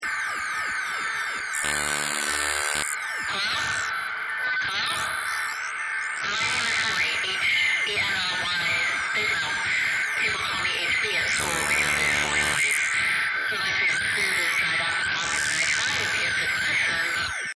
A snippet from a border blaster boosted by an electrical storm.
Headphone warning! High-pitched noises.